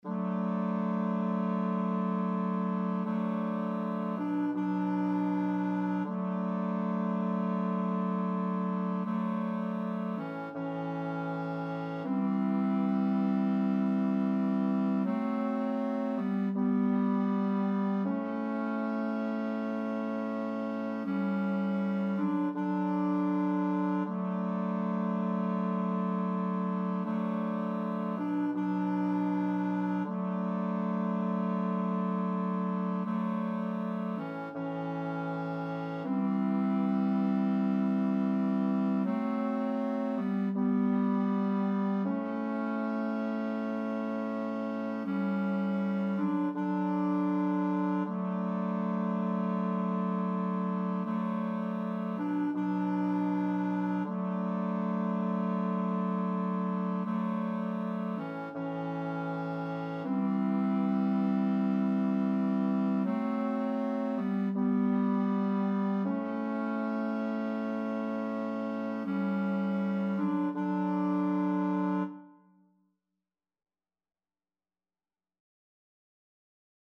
Title: De profundis Composer: Anonymous (Traditional) Lyricist: Number of voices: 3vv Voicing: TTB Genre: Sacred, Sacred song
De_profundis_TTB.mp3